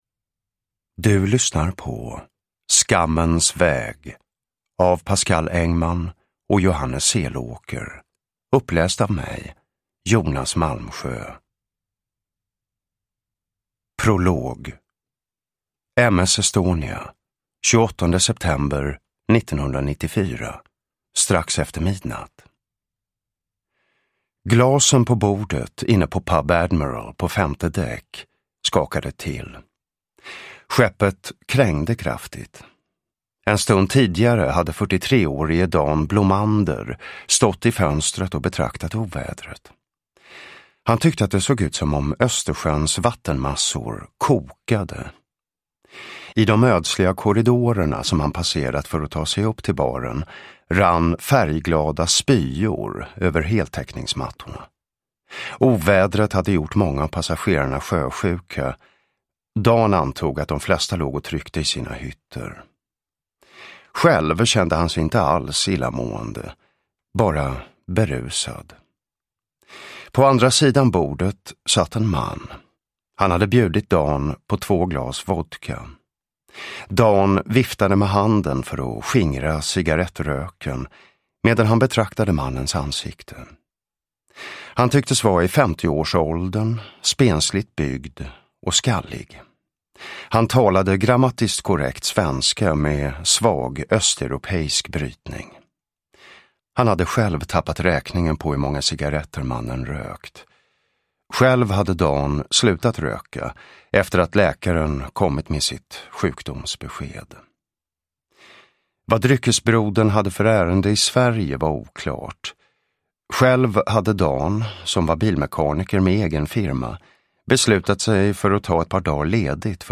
Skammens väg – Ljudbok – Laddas ner
Uppläsare: Jonas Malmsjö